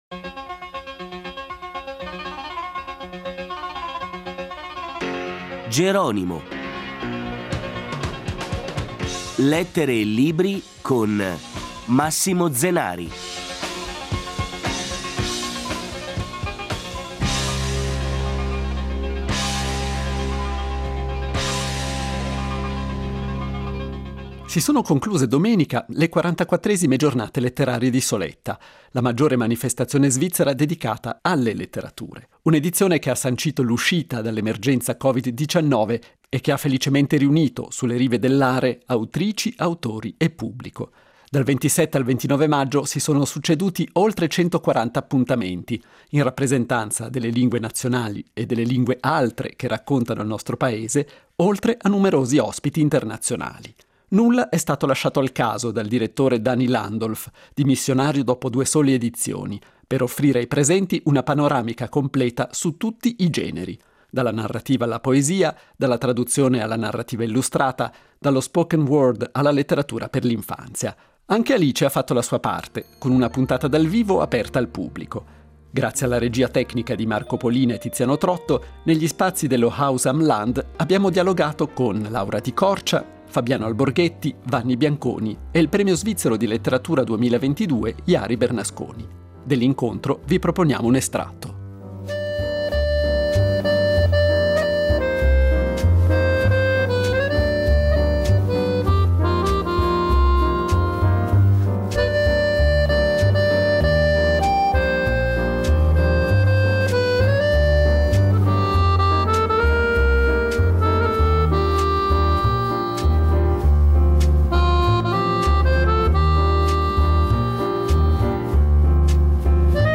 In questa puntata si fa ritorno alle Giornate letterarie di Soletta , la cui 44esima edizione si è svolta dal 27 al 29 maggio. Con una puntata speciale in diretta, aperta al pubblico, Alice ha seguito la maggiore manifestazione nazionale di letteratura dalle rive dell’Aare.